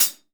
Perc (112).WAV